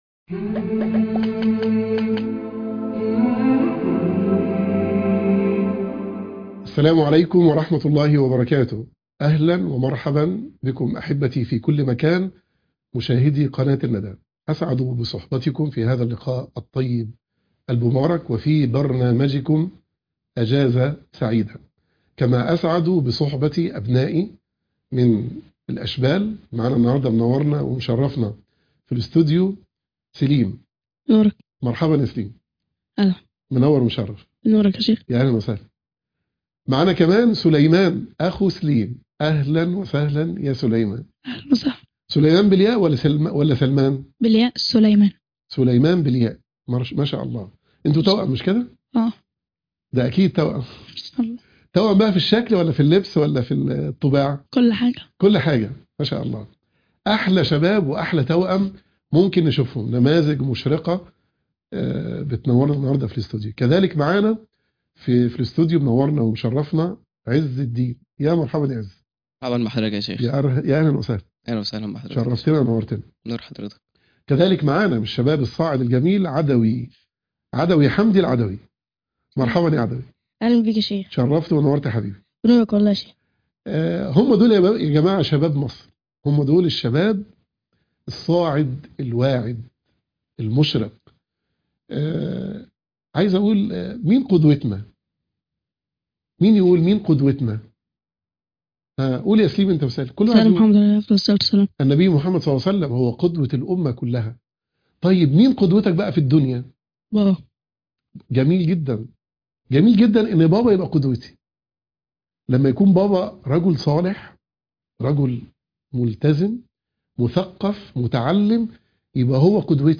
ومجموعة من الأطفال